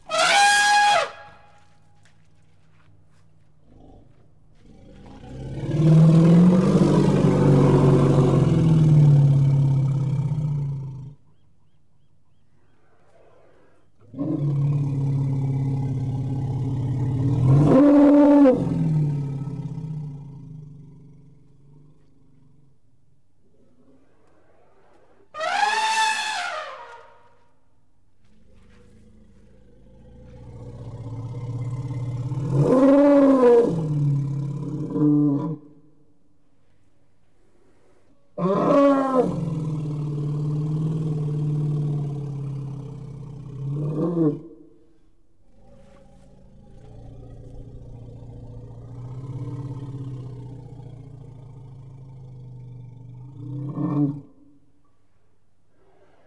elephant-shouting-voice